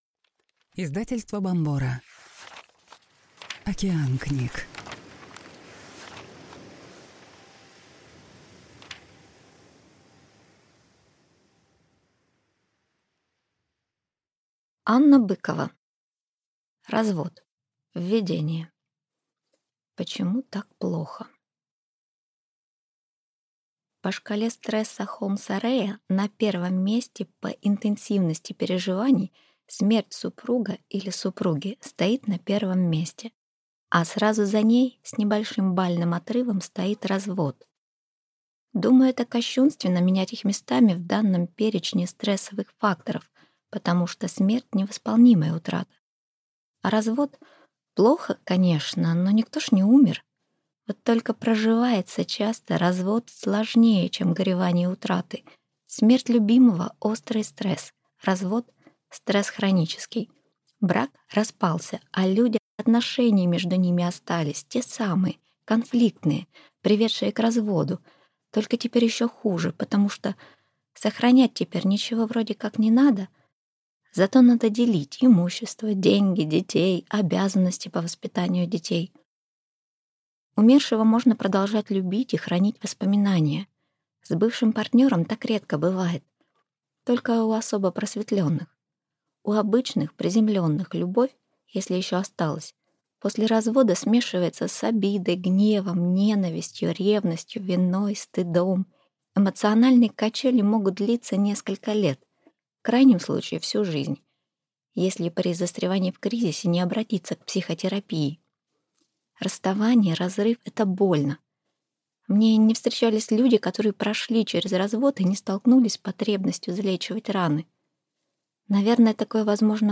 Аудиокнига Развод и как в нем уцелеть | Библиотека аудиокниг